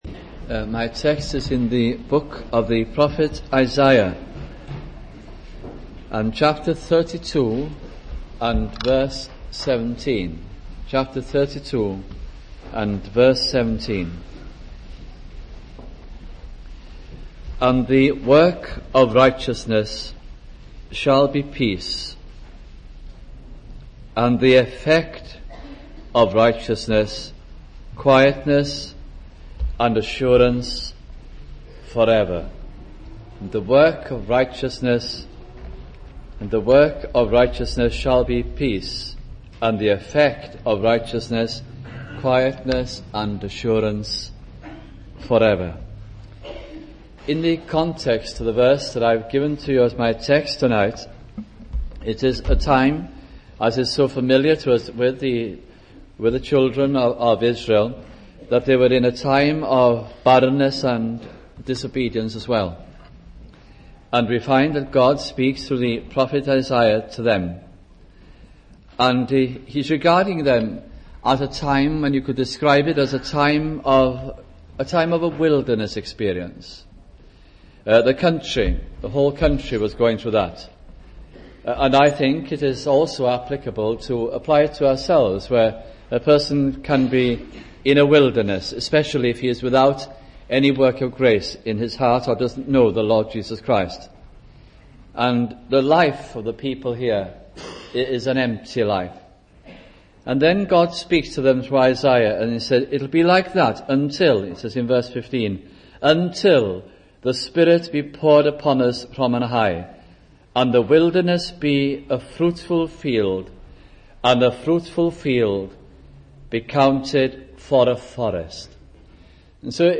» Isaiah Gospel Sermons